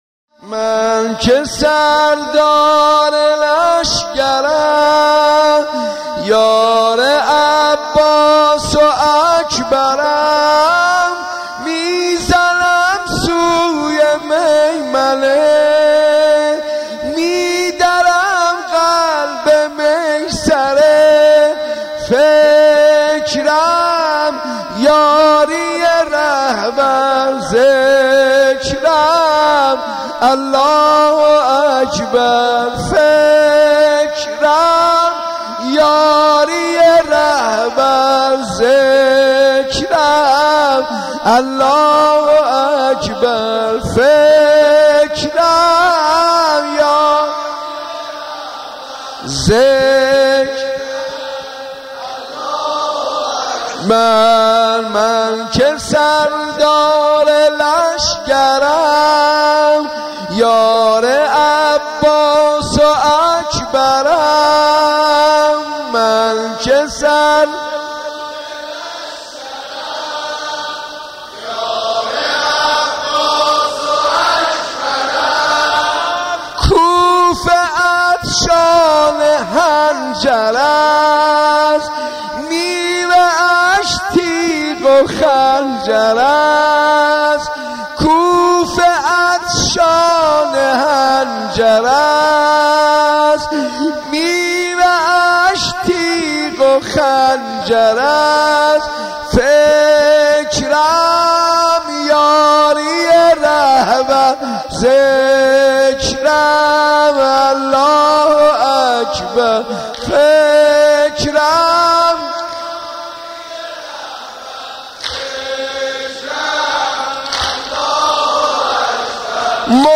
مناسبت : شب دوم محرم
مداح : حاج منصور ارضی قالب : زمینه